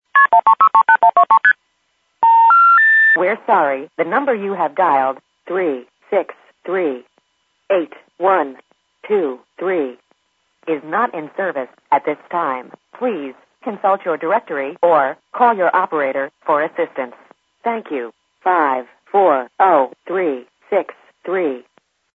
Automated Intercept Local Telephone Company Recordings
Automated intercept recordings inform the caller the number that was dialed and the reason why the call did not complete.
This is an example of a not in service announcement with MF tones.